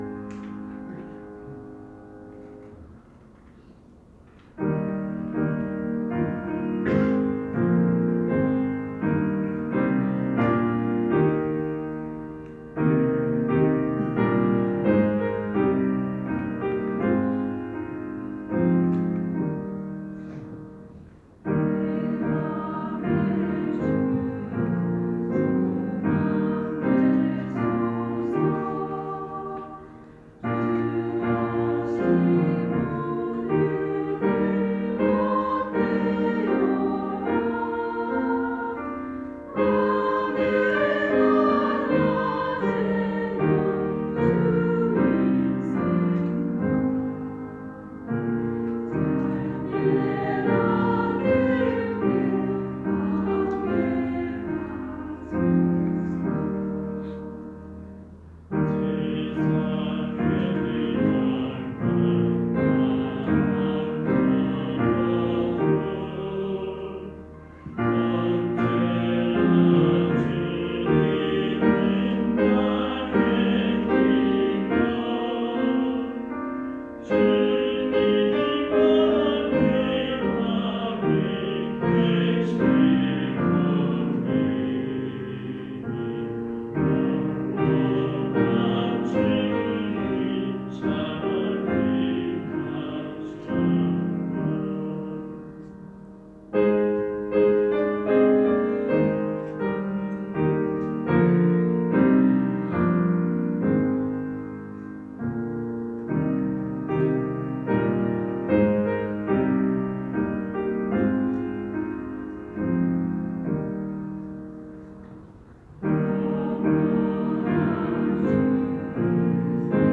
주일찬양